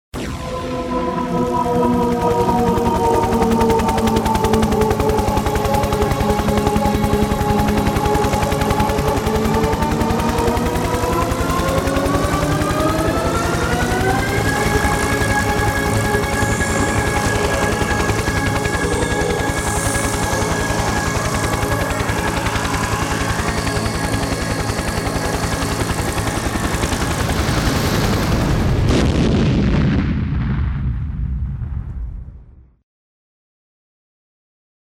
Звуки полета